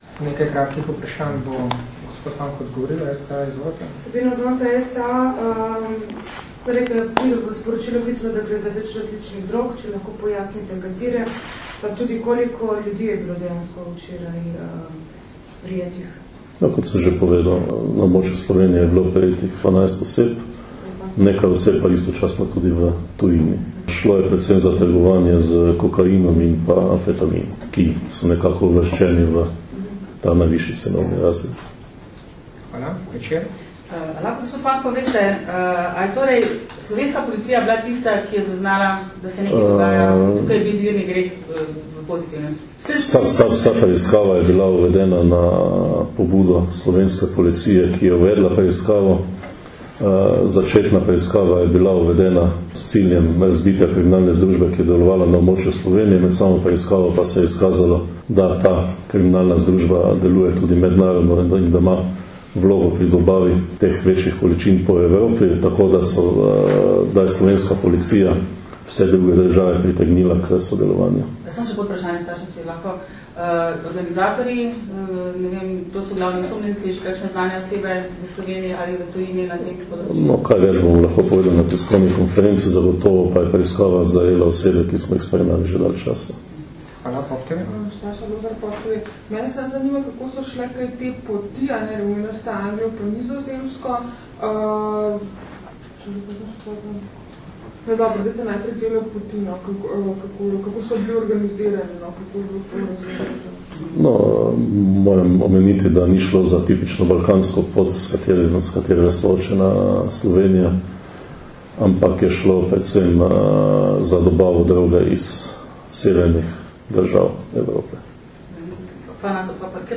Novinarska vprašanja in odgovori nanje (mp3)